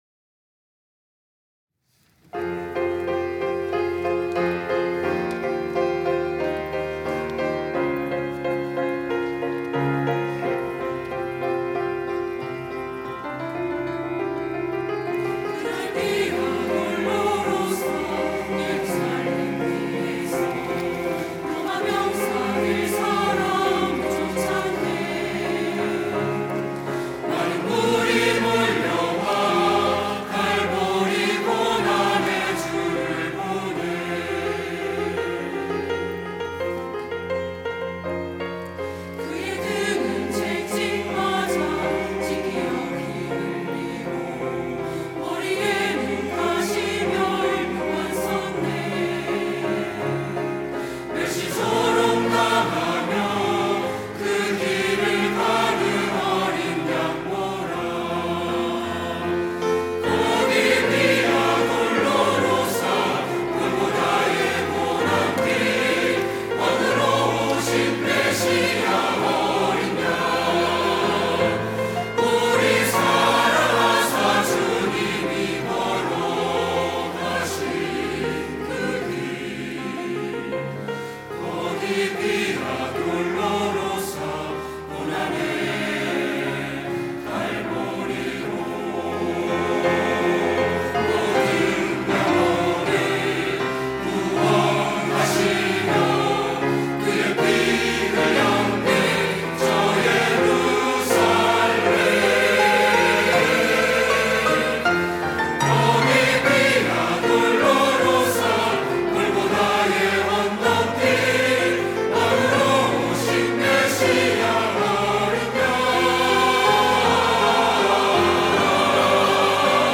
시온(주일1부) - 비아 돌로로사
찬양대